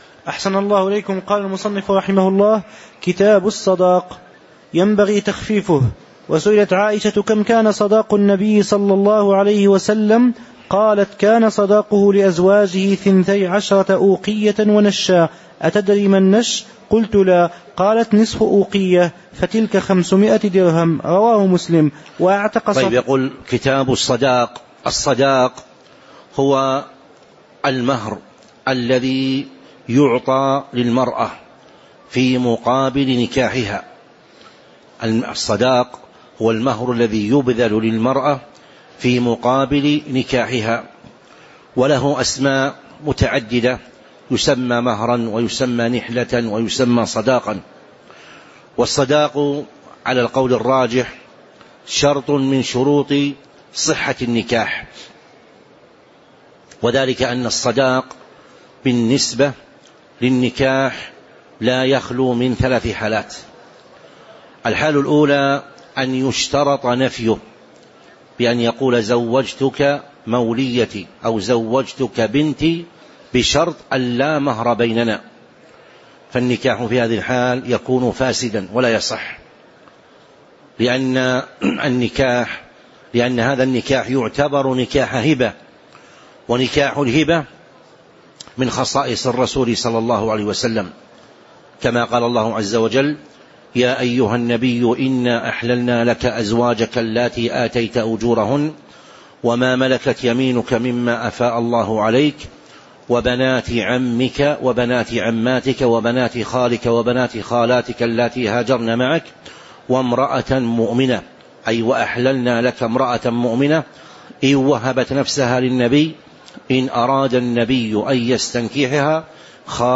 تاريخ النشر ٢٤ جمادى الآخرة ١٤٤٦ هـ المكان: المسجد النبوي الشيخ